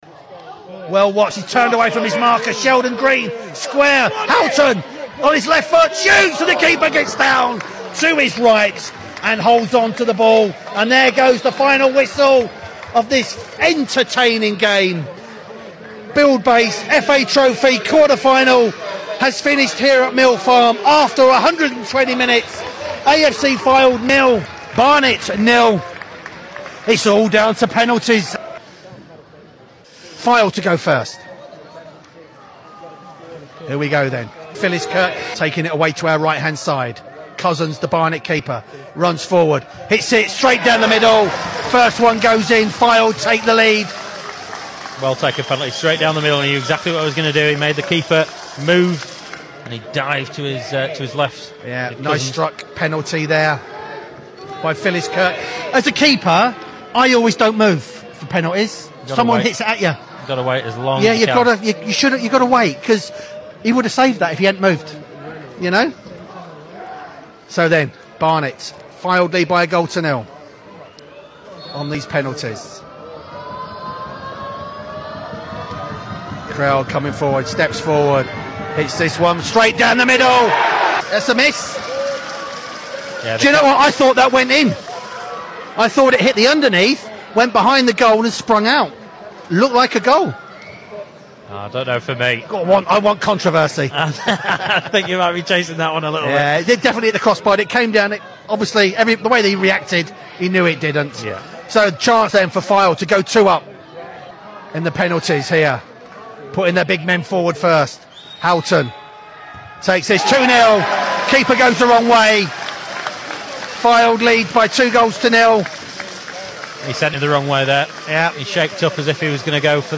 Highlights